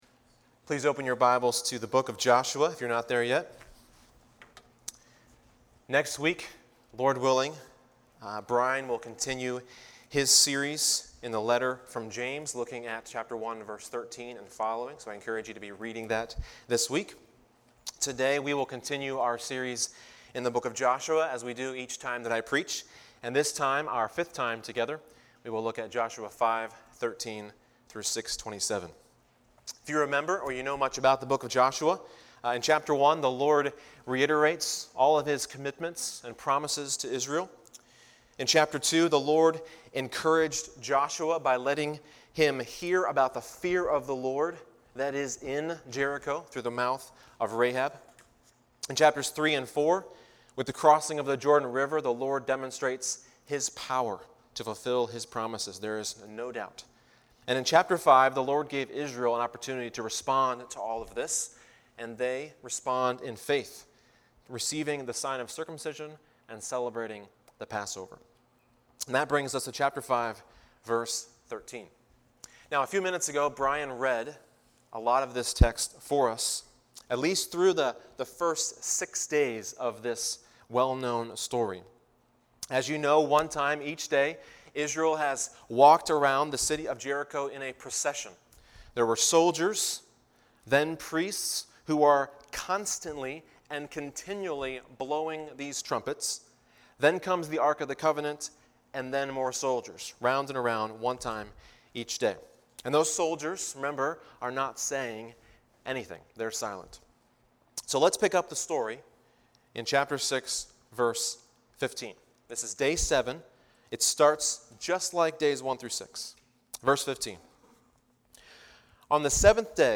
A message from the series "Joshua."